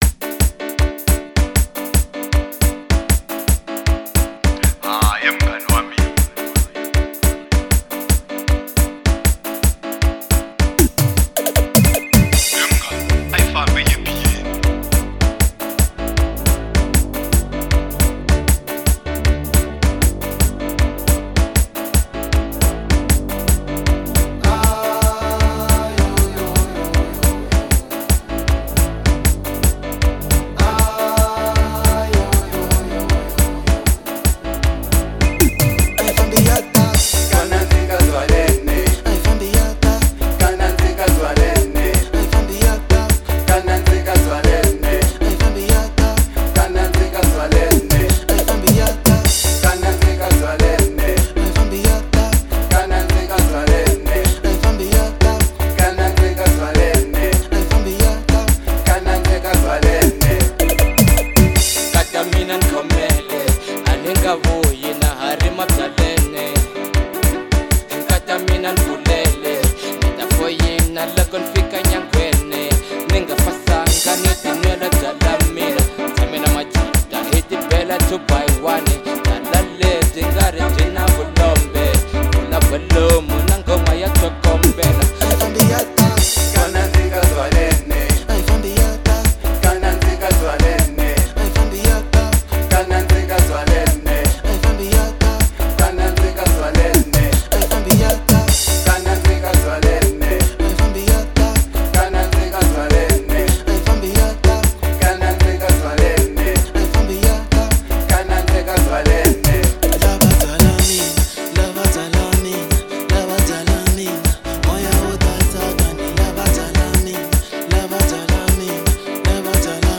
04:17 Genre : Xitsonga Size